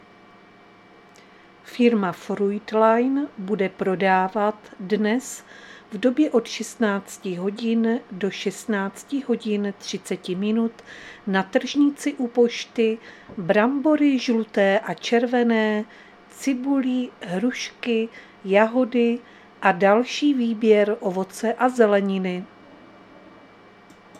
Záznam hlášení místního rozhlasu 31.3.2025
Zařazení: Rozhlas